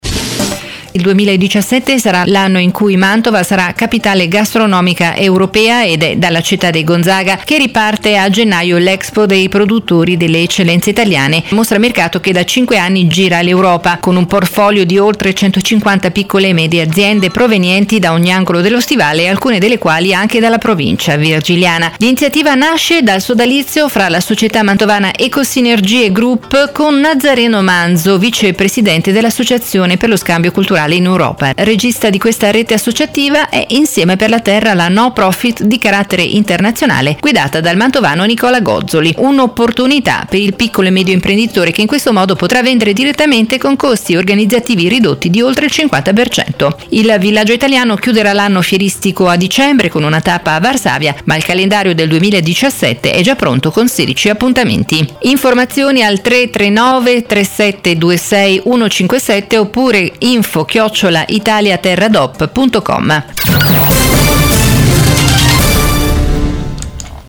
Di seguito l’annuncio sul giornale radio di Radio Bruno: